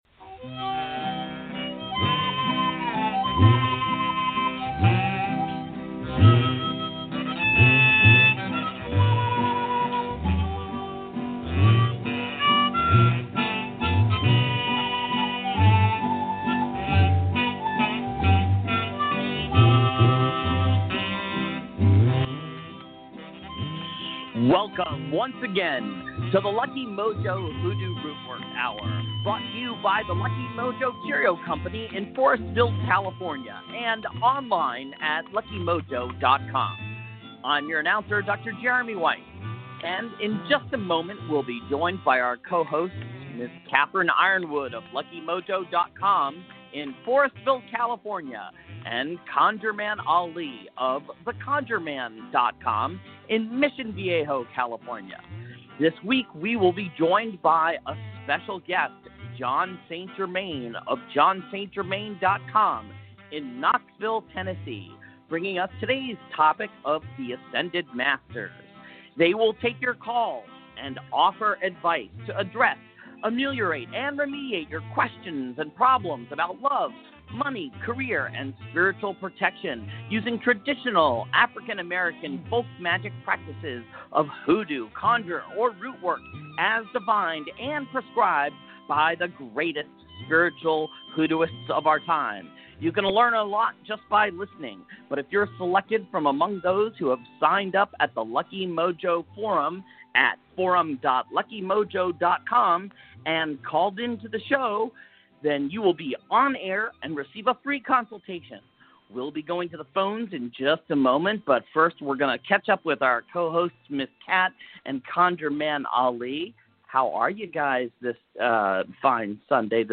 discussion panel